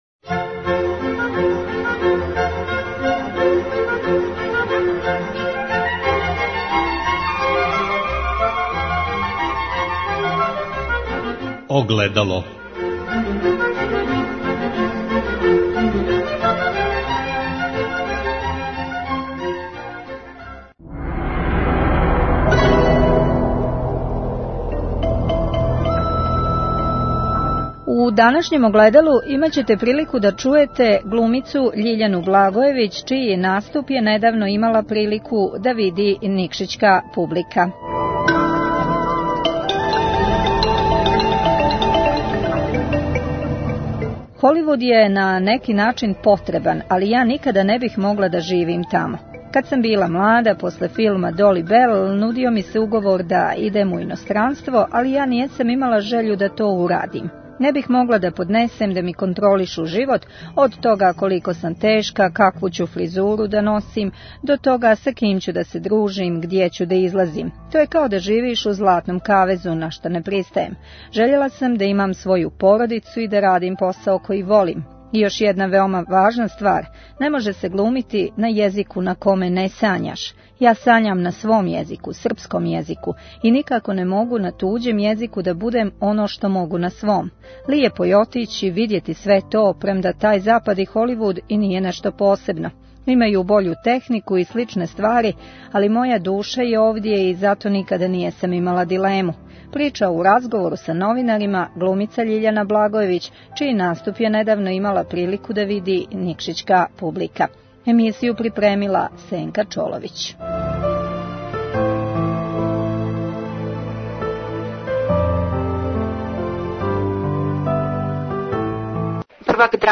Имају бољу технику и сличне ствари, али, моја душа је овде и зато никад нисам имала дилему“, прича у разговору са новинарима глумица Љиљана Благојевић, чији наступ је недавно имала прилику да види никшићка публика.